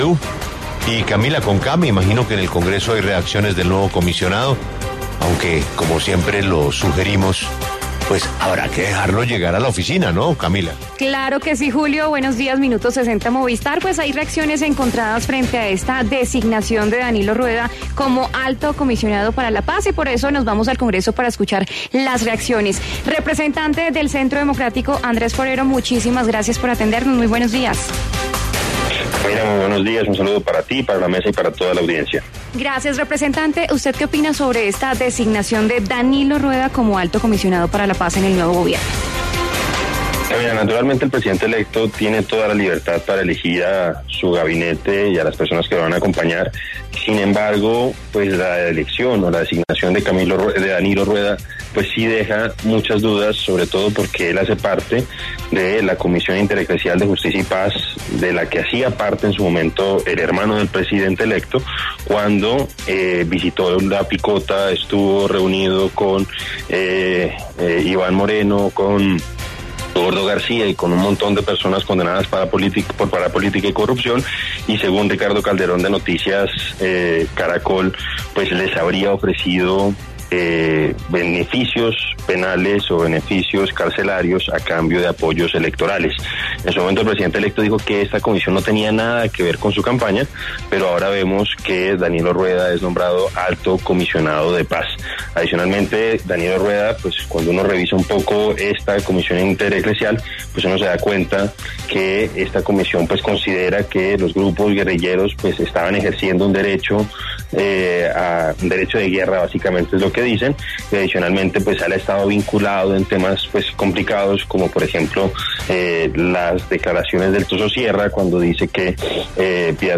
En La W participaron los representantes a la Cámara, Andrés Forero y Gabriel Becerra.
Gabriel Becerra y Andrés Forero se pronuncian sobre el nombramiento de Danilo Rueda